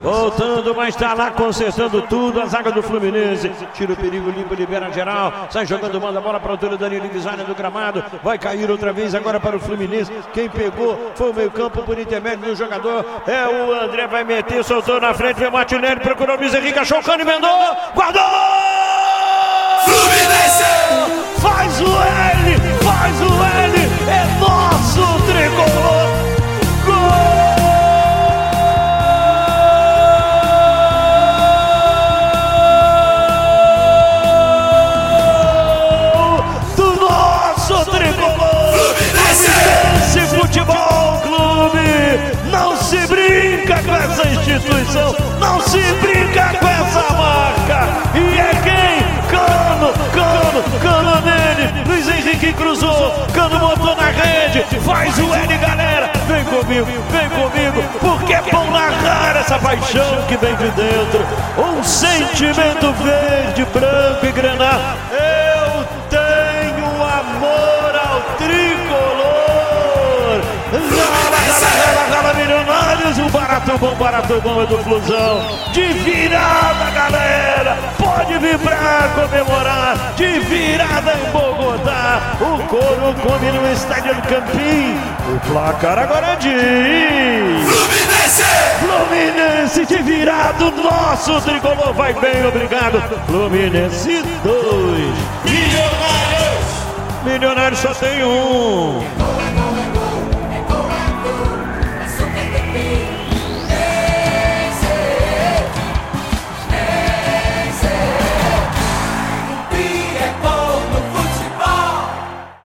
David Braz e Cano marcaram nos 2 a 1, em Bogotá, na Colômbia